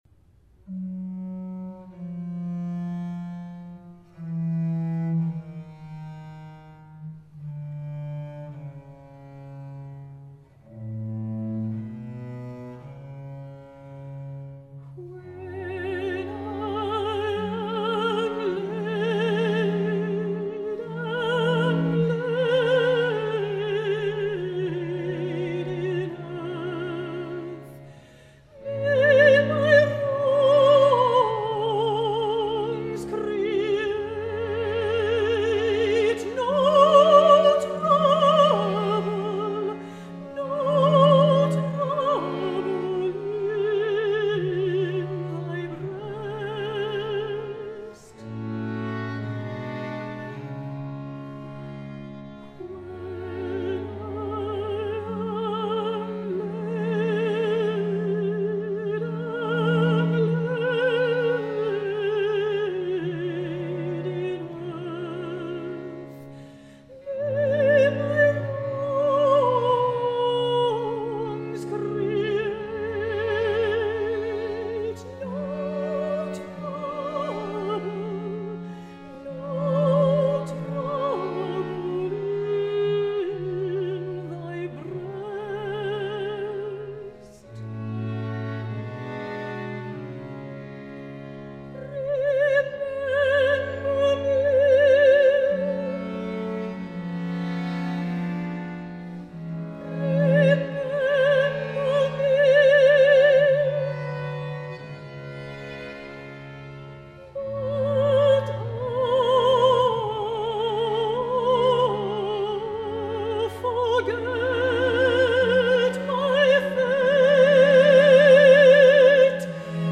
arija
dainininkas su instrumento(ų) ar viso orkestro pritarimu
uždaras solinis vokalisto numeris, kuriame išreiškiamas vienas jausmas ar nuotaika